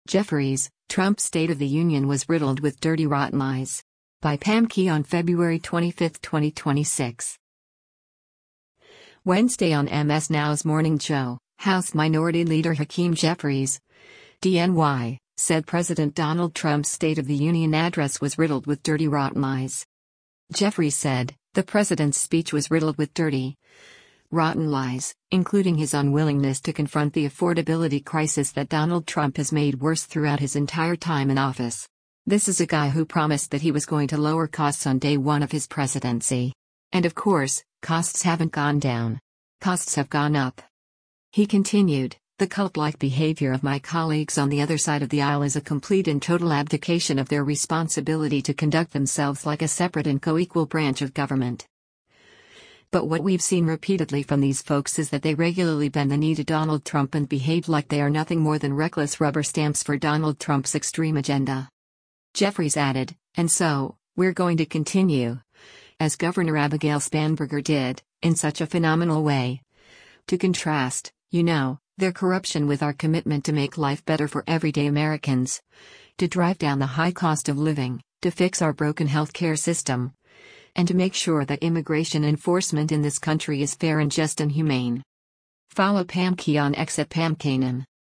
Wednesday on MS NOW’s “Morning Joe,” House Minority Leader Hakeem Jeffries (D-NY) said President Donald Trump’s State of the Union address was “riddled with dirty rotten lies.”